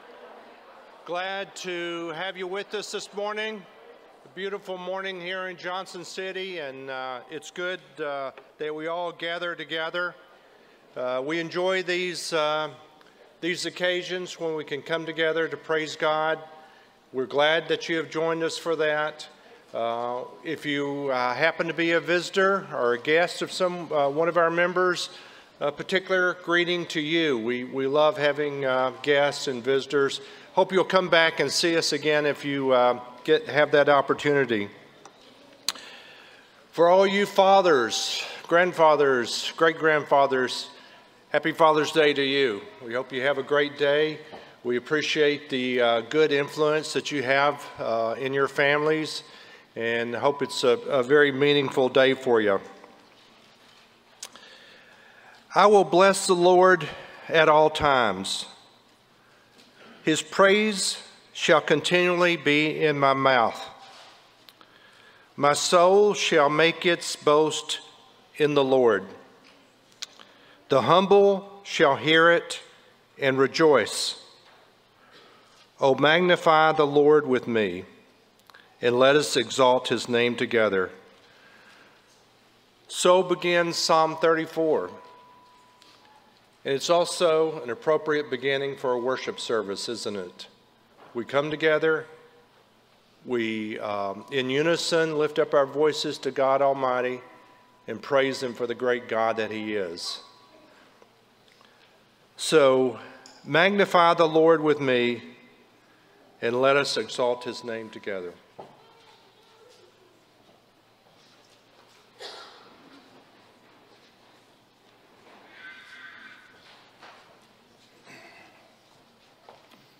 John 15:11, English Standard Version Series: Sunday AM Service